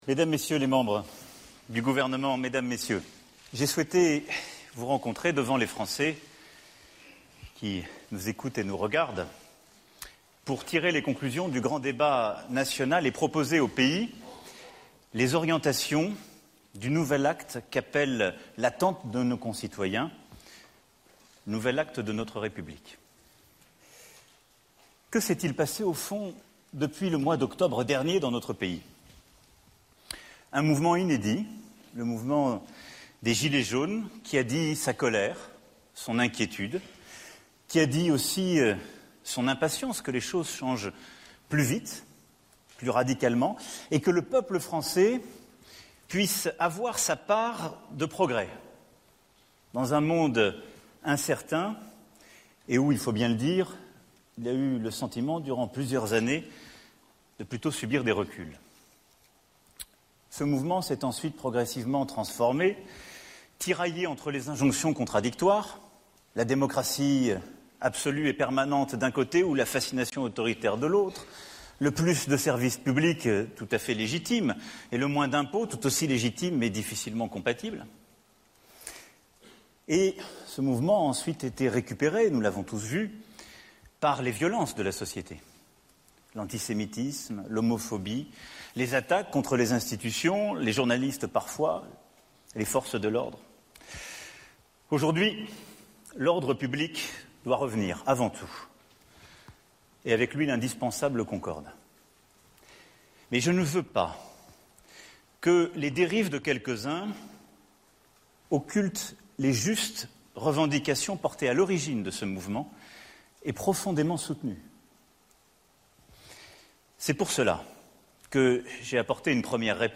Le président Francais, Emmanuel Macron, a annoncé, lors d'une conférence de presse au palais de l'Elysée, une série de mesures destinées à apporter des réponses au grand débat national.